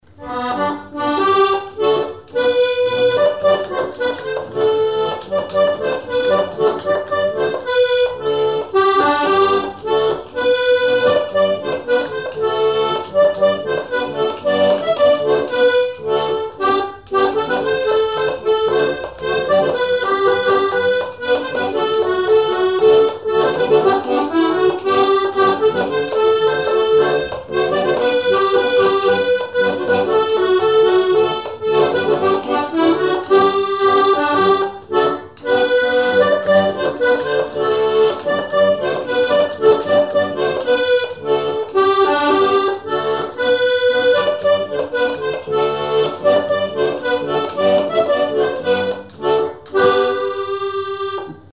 Mémoires et Patrimoines vivants - RaddO est une base de données d'archives iconographiques et sonores.
Témoignages et chansons
Pièce musicale inédite